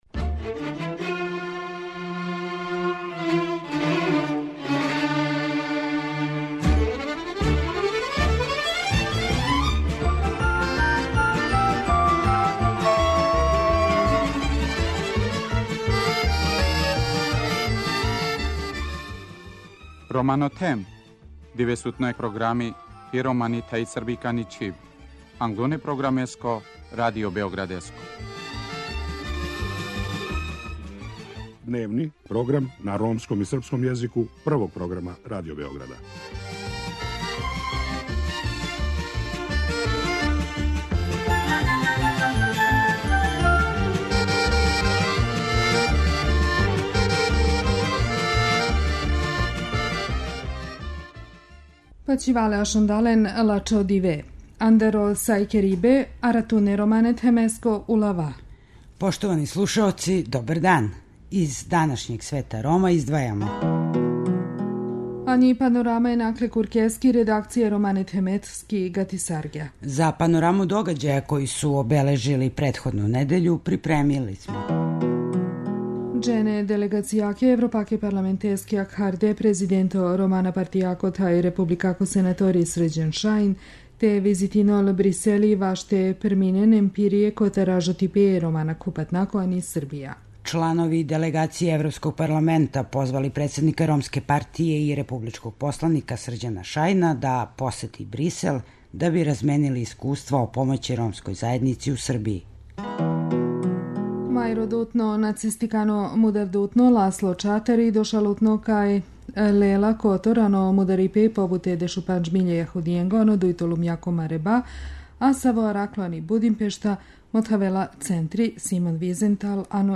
У нашој данашњој емисији слушаћете једну стару ромску причу.